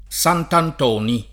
[ S antant 0 ni ]